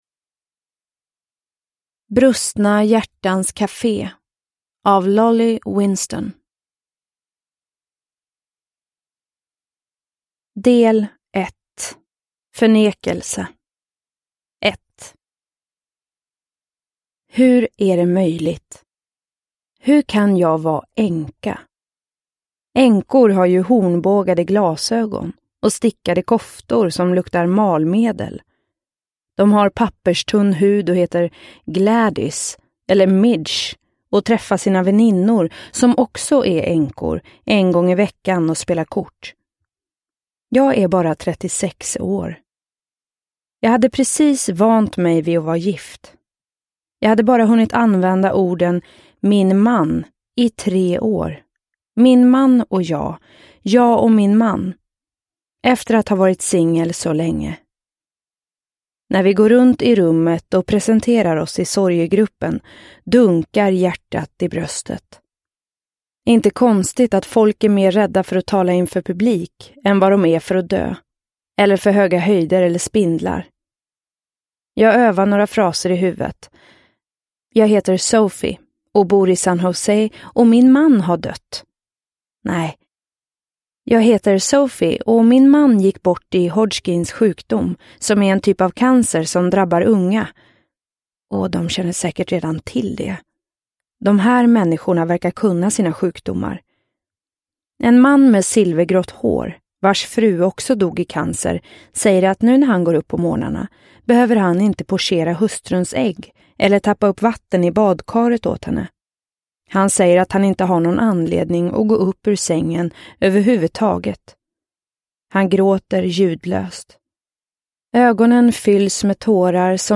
Brustna hjärtans café – Ljudbok – Laddas ner